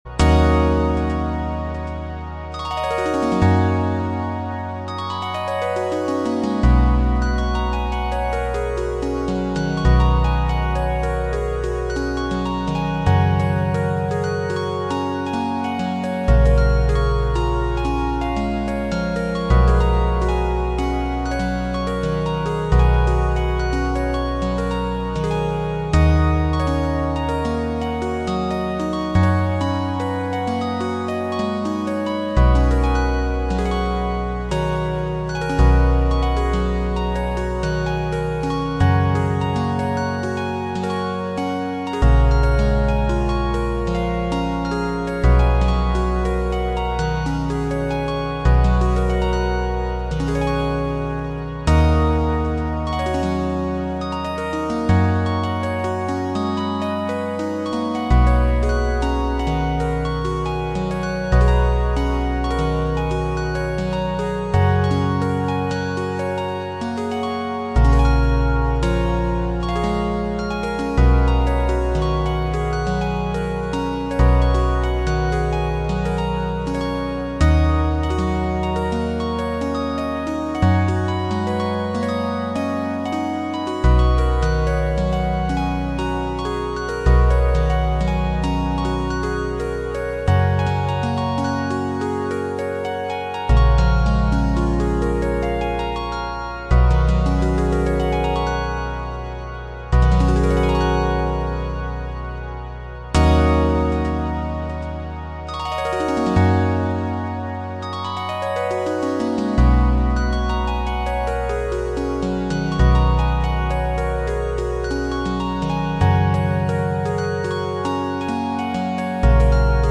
A New And Fast Polyrhythm Sound Effects Free Download
a new and fast polyrhythm